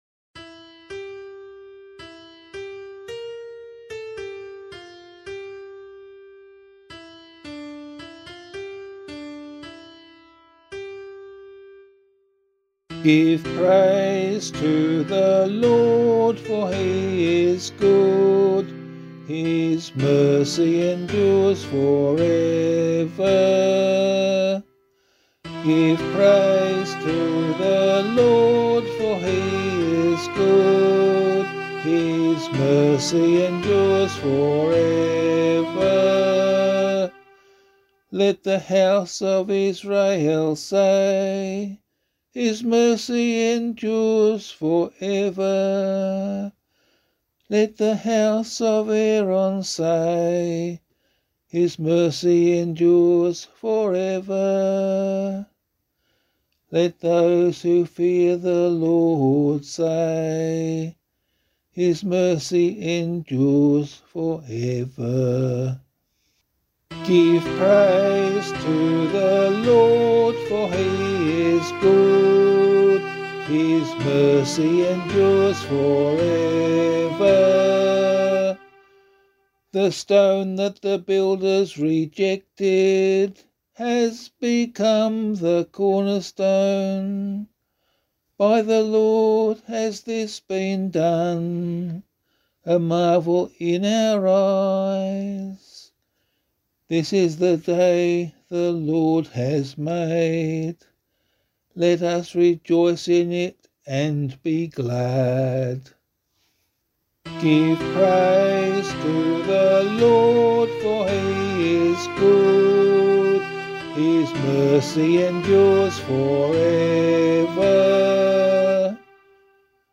024 Easter 2 Psalm C [APC - LiturgyShare + Meinrad 7] - vocal.mp3